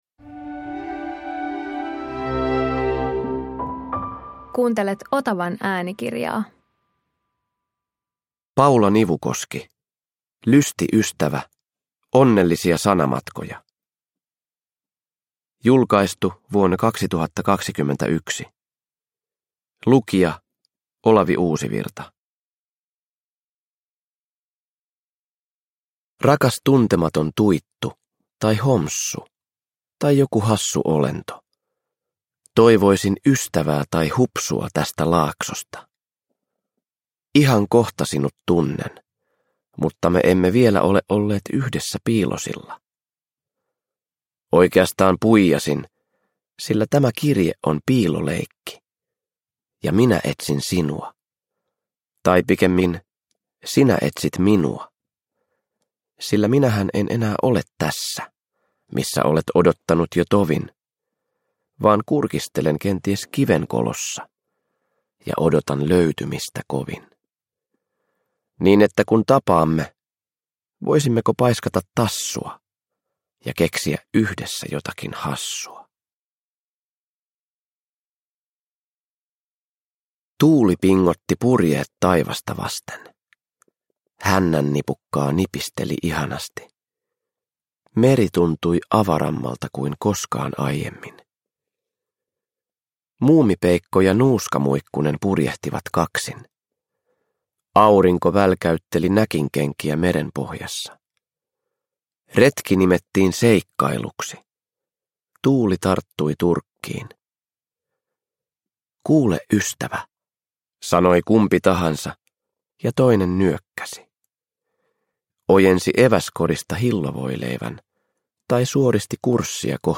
Uppläsare: Olavi Uusivirta